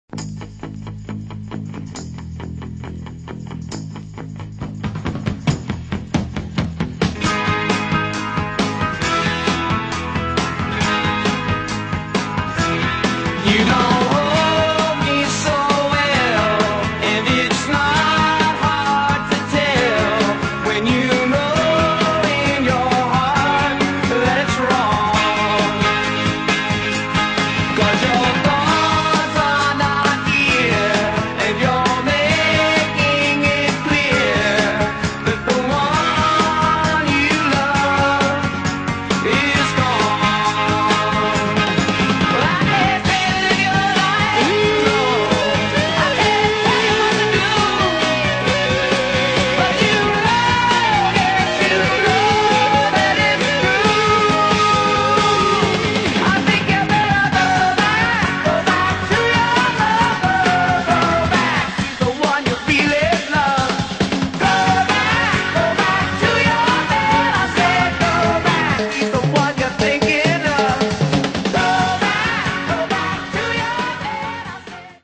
California rock group
The great audio is almost Mint as well.